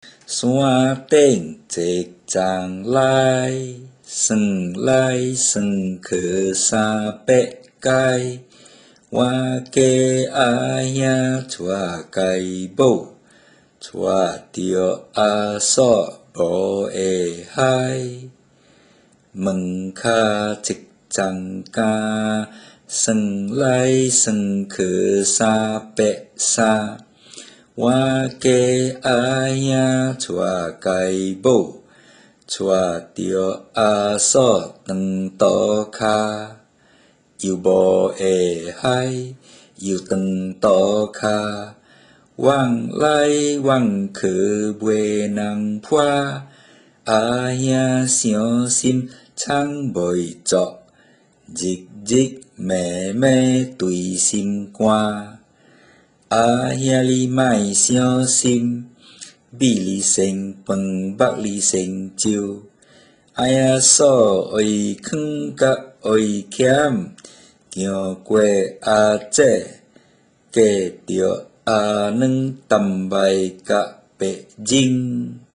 Recite
HillTop_Recite.mp3